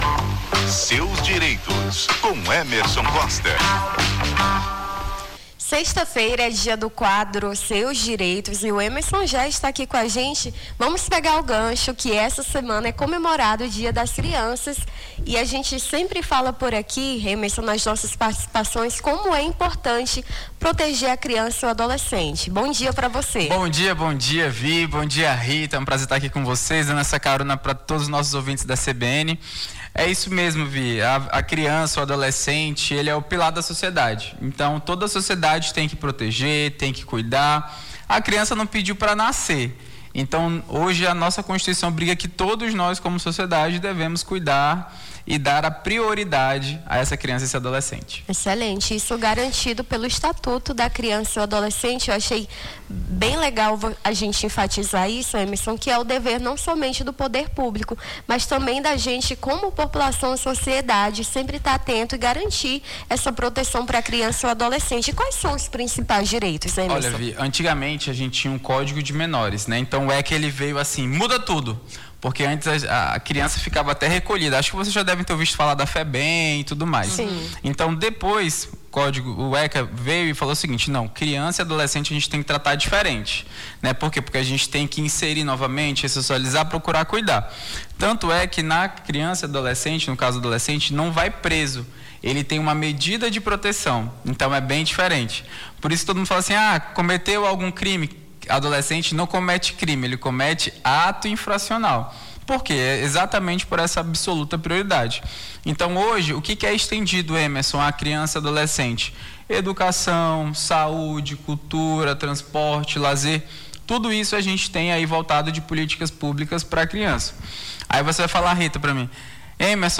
Seus Direitos: advogado esclarece dúvidas sobre o direito da criança e do adolescente